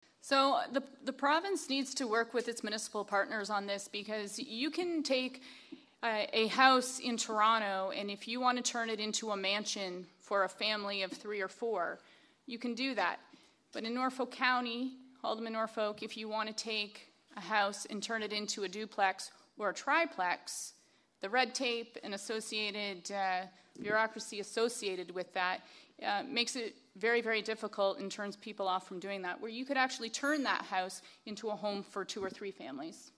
The Haldimand-Norfolk candidates met at the Royal Canadian Legion in Simcoe on Thursday night.